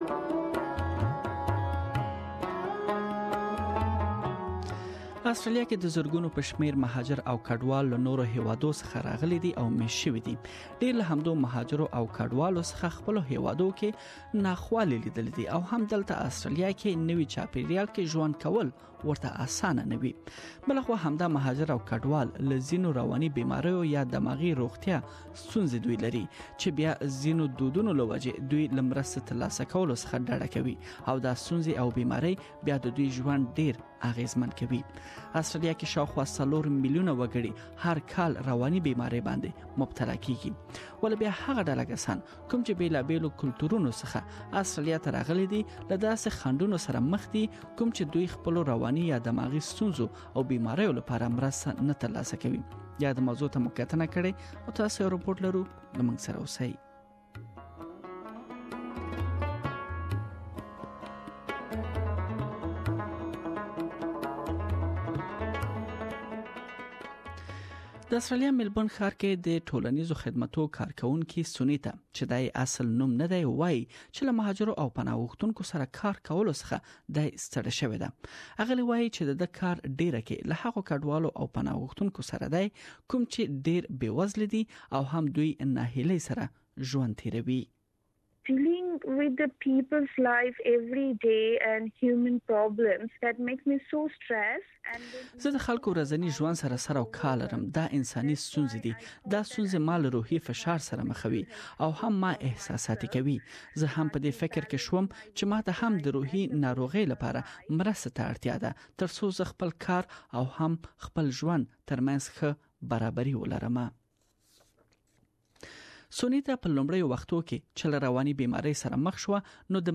يادې موضوع ته مو کتنه کړې او تاسو ته رپوټ لرو دلته بشپړ رپوټ اوريدلی شئ.